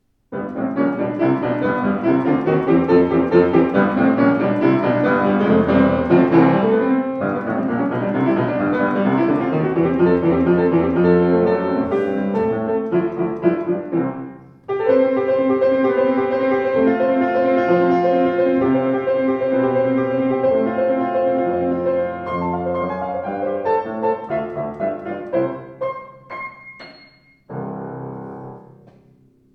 Klangproben eines anderen, baugleichen Steinway Z: